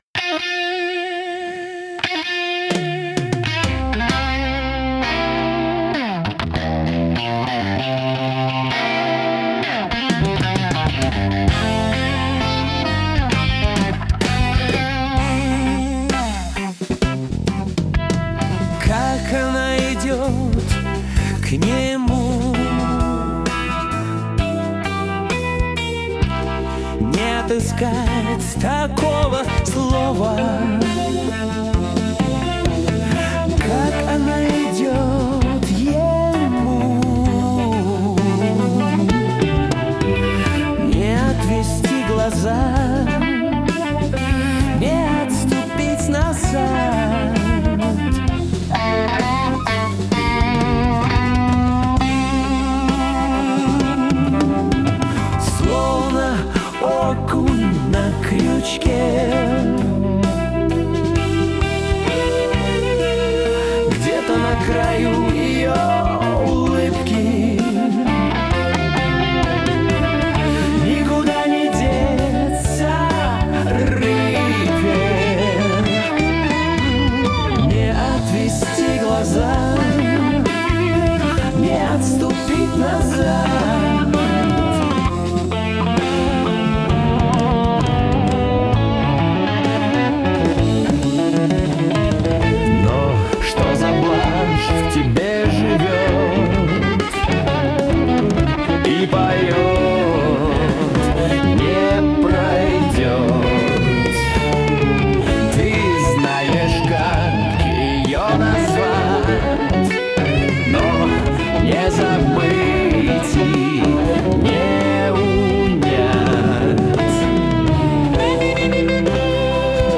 Музыкальный хостинг: /Рок